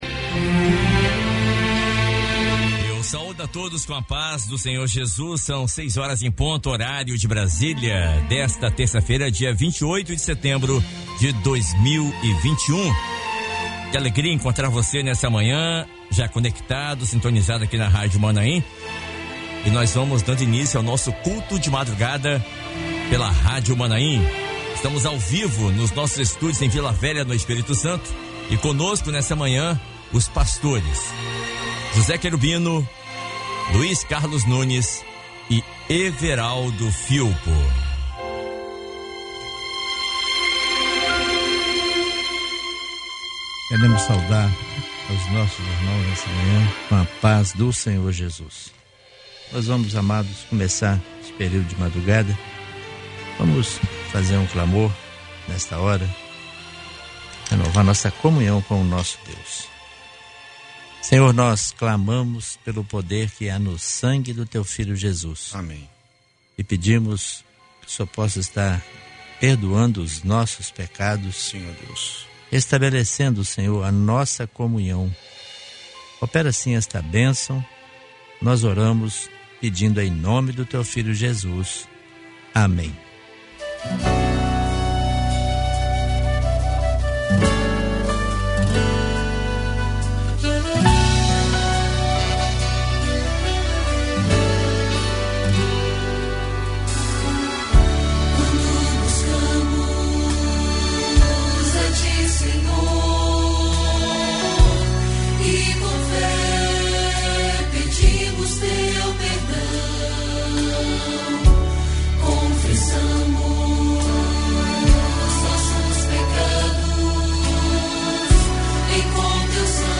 Culto de oração transmitido dentro do programa Bom Dia Maanaim